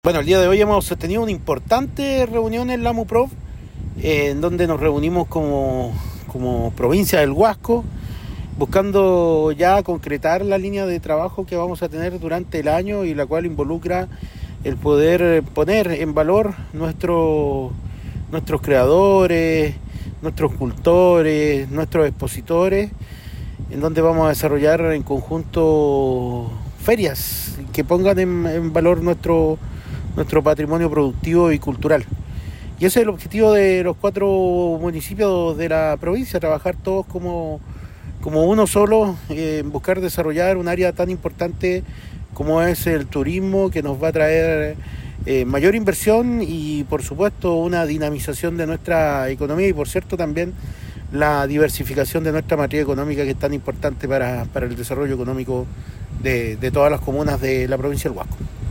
Alcalde-de-Vallenar-Victor-Isla.mp3